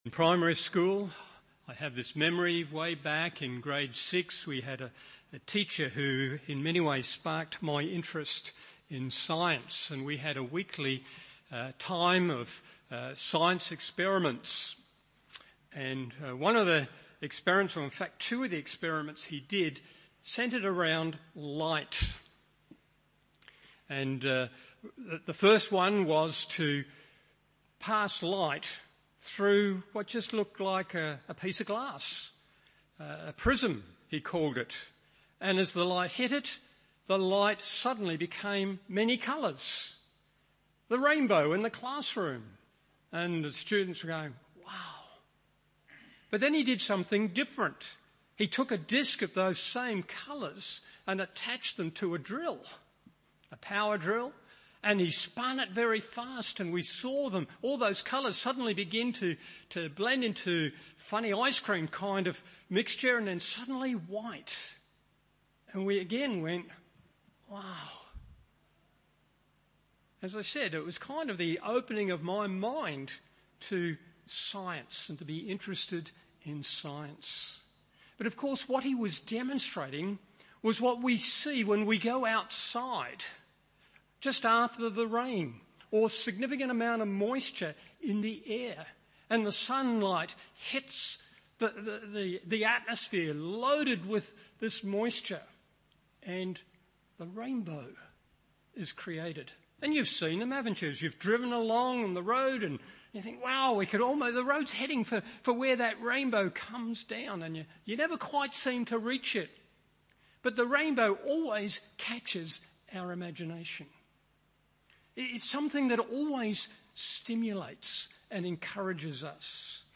Morning Service Genesis 9:1-17 1. Opportunities Before Us 2. Sin within Us 3.